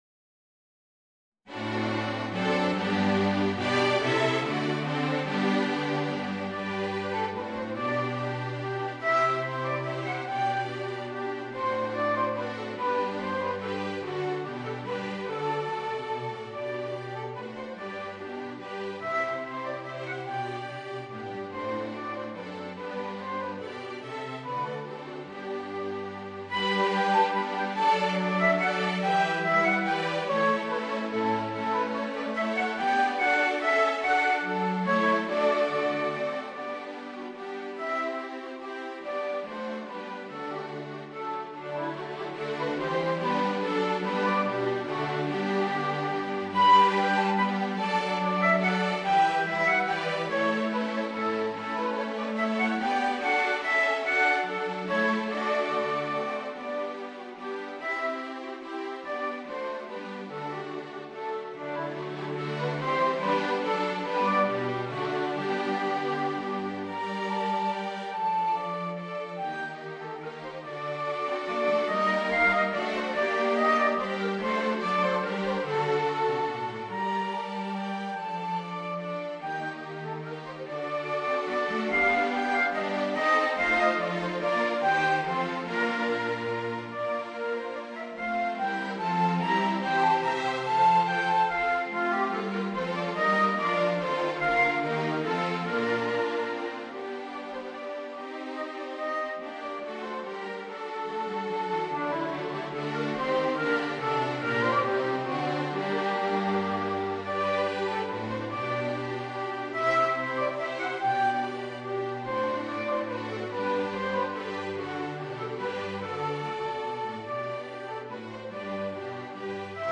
Voicing: Euphonium and String Quartet